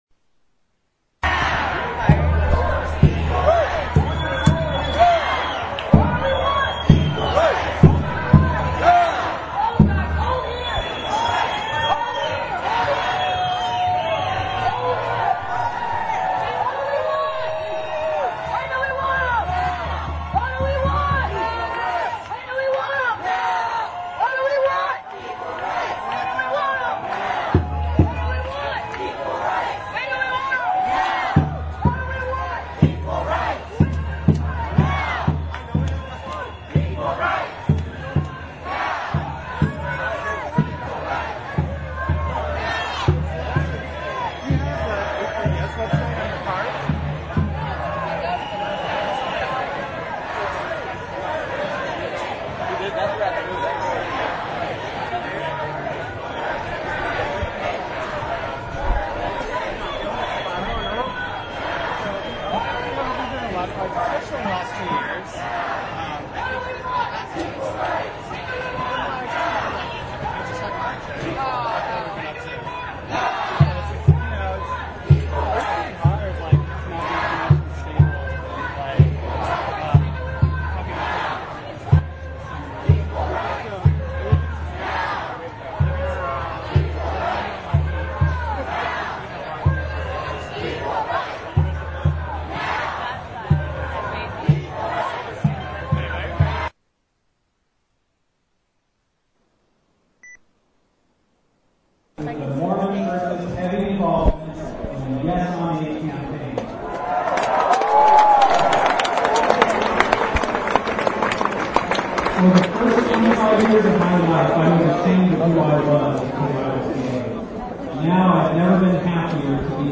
audio excerpts from the November 15th rally at City Hall; hear three speakers, including a Mormon working against Proposition 8; a lesbian mother; and a Latino man speak about their individual issues and experiences, along with announcements about organizing strategies and upcoming events to overturn Proposition 8; also, hear chants from the march down Market Street.
The rally was packed, all the way from the steps of City Hall throughout most of Civic Center Plaza; the crowd included a large, boisterous cross section of San Franciscans and others around the Bay Area.
After the rally, the crowd took to Market Street with a police escort, chanting enthusiastically.